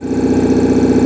1 channel
canon_machine01.mp3